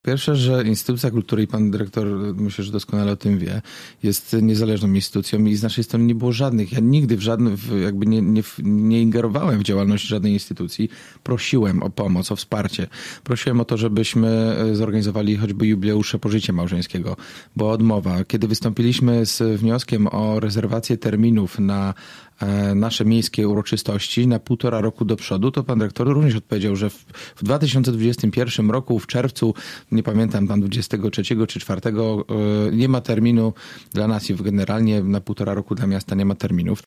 Prezydent komentuje odejście dyrektora filharmonii